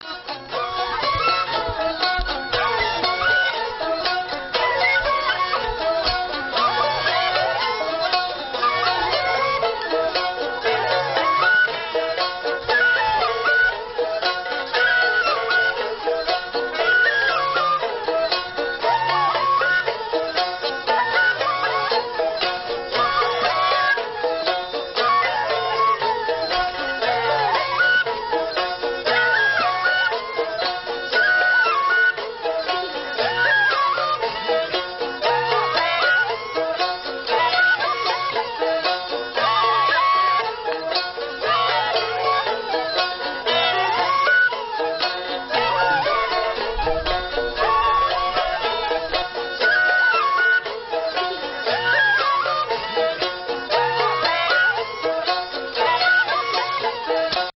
三弦独奏 -下载地址列表-乐器学习网
0011-三弦名曲三弦独奏.mp3